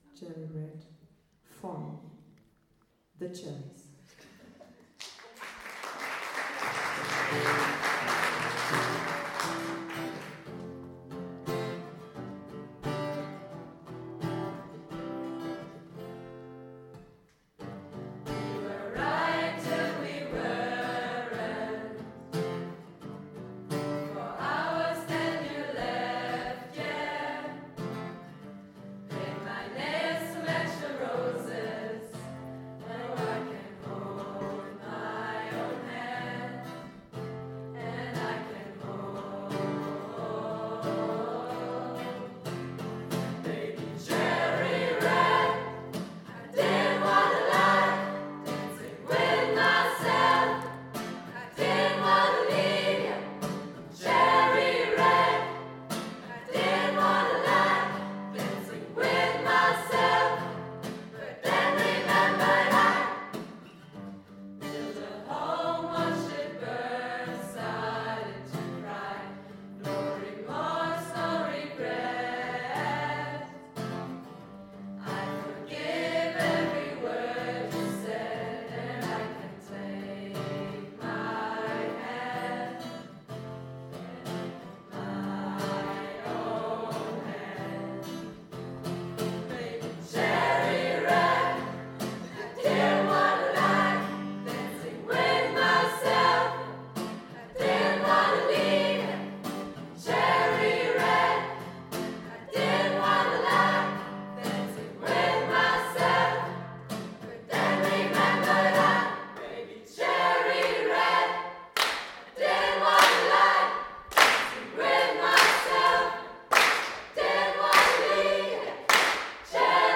(Live-Mitschnitt der Zugabe “Cherry Red”)
Auftritt in der wunderschönen Hospitalkirche in Schwäbisch Hall !